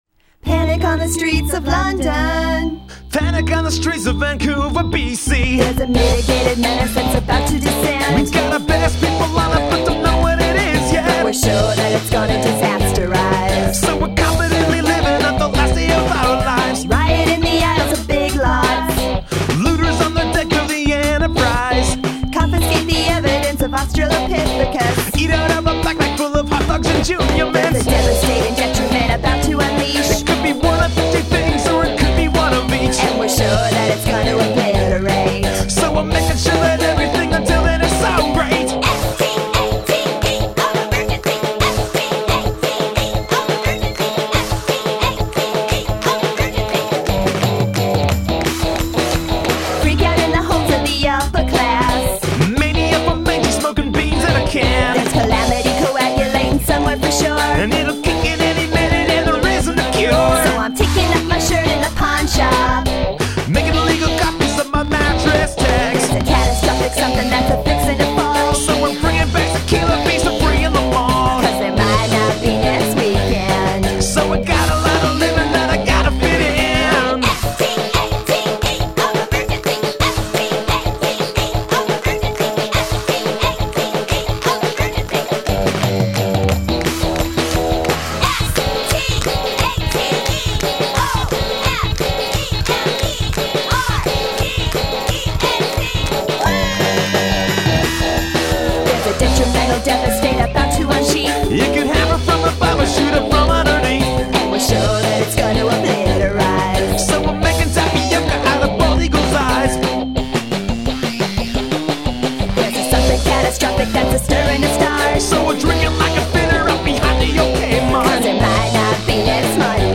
Guest Vocals